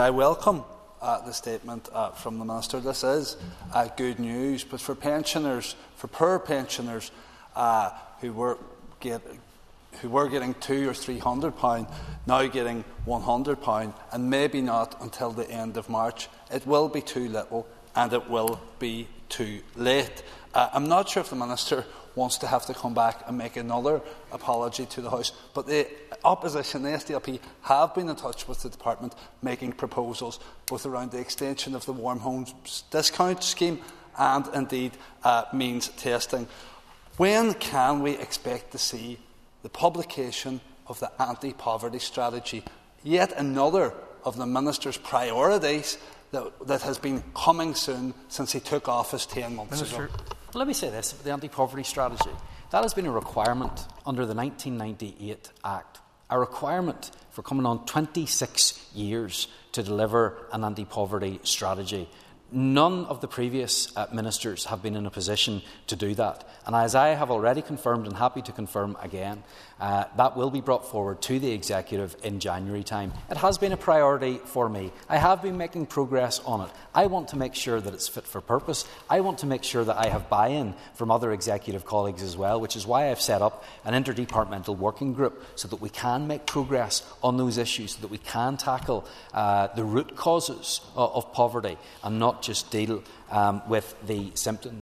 There were exchanges in the Stormont Assembly this afternoon between Foyle MLA Mark Durkan and Communities Minister Gordon Lyons, after the minister announced a once off payment of £100 pensioners who will lose out when the Winter Fuel Payment is means tested, a requirement imposed by Westminster which Mr Lyons described as unwelcome and unexpected.